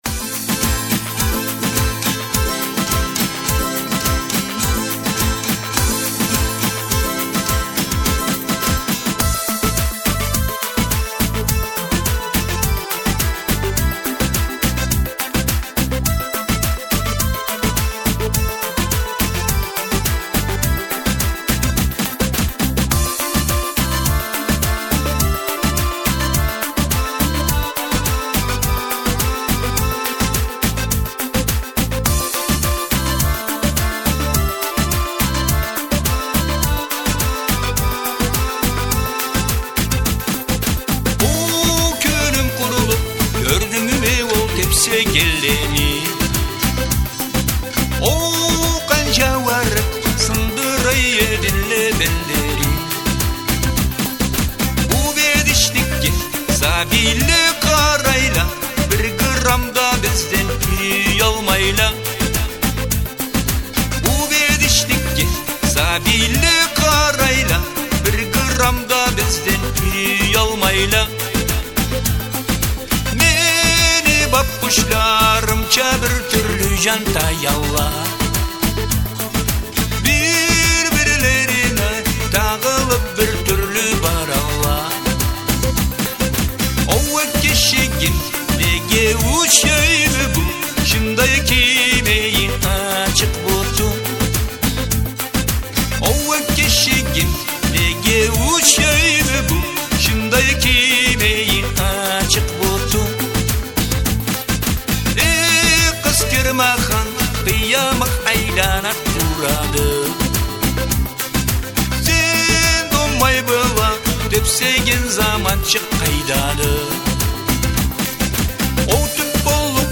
чам джыр